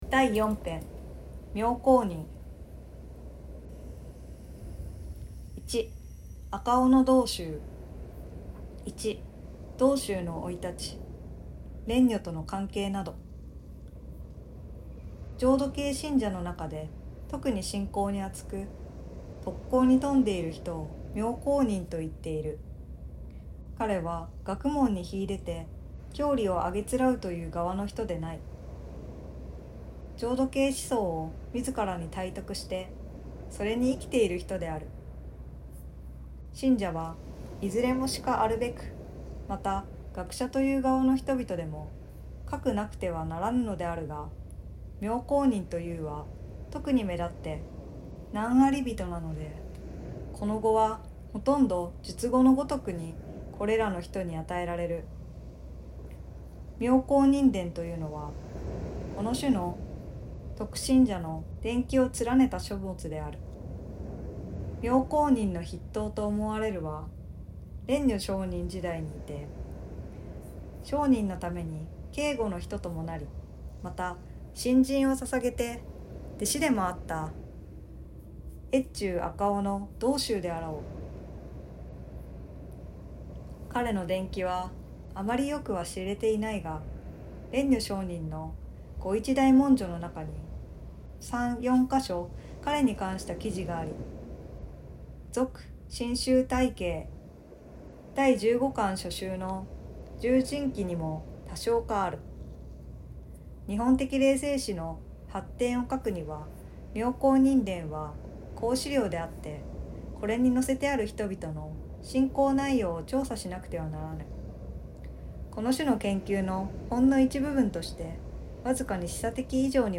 心を豊かにする朗読。